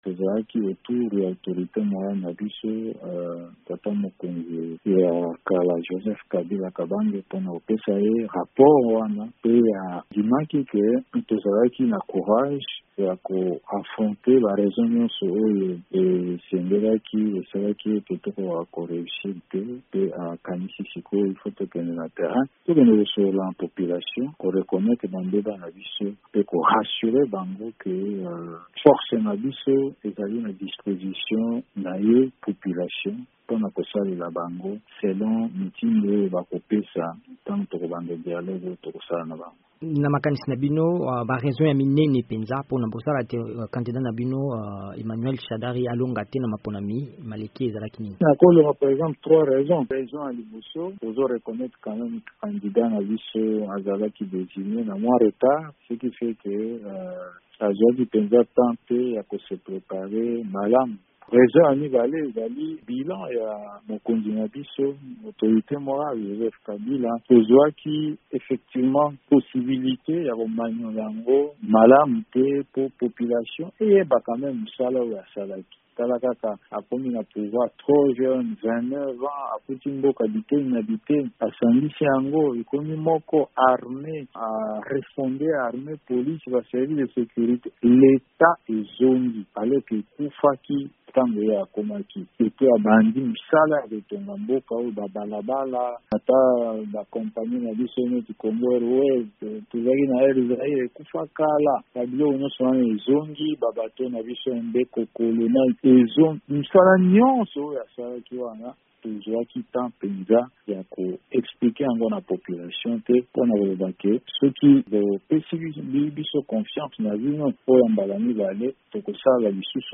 Néhémie Mwilanya ayanoli na mituna nsima na bokutani ya FCC na Kingakati
VOA Lingala epasaki maloba na motambwisi ya lisanga linene liye, Néhémie Mwilanya.